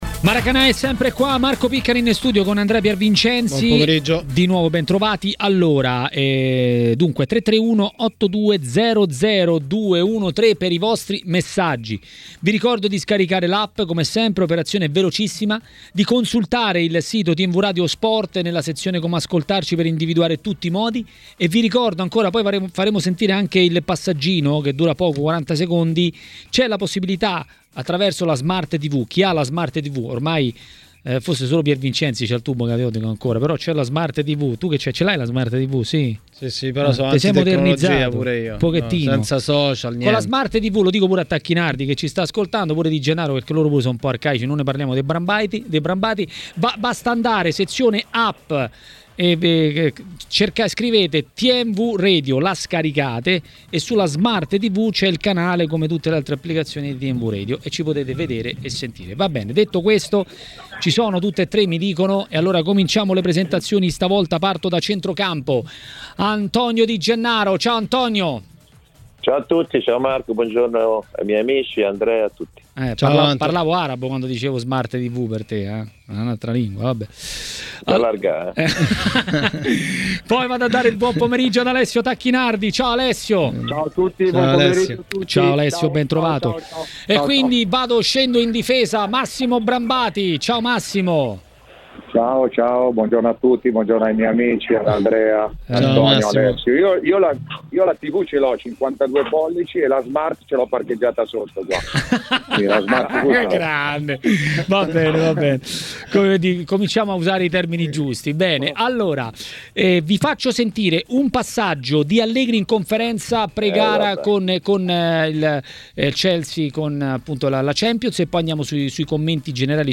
L'ex calciatore e tecnico Alessio Tacchinardi a TMW Radio, durante Maracanà, ha parlato di Juventus e non solo.